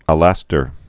(ə-lăstər, -tôr)